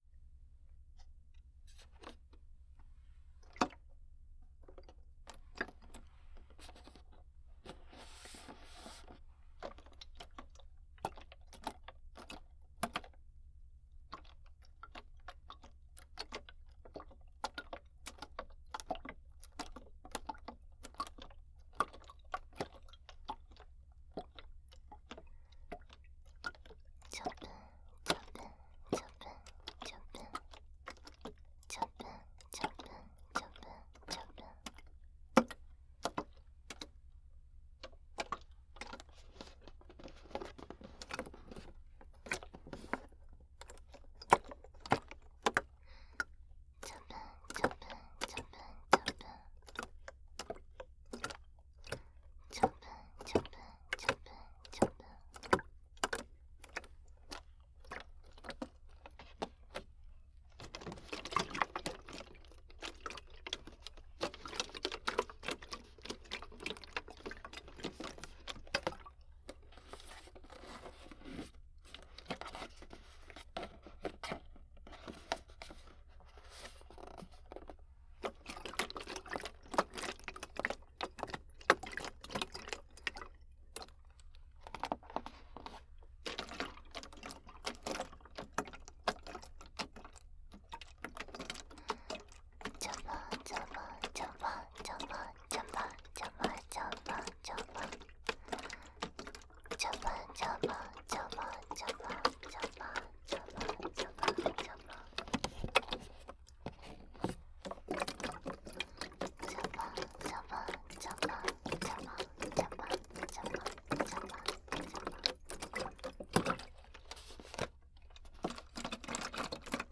02.水音パートのみ.wav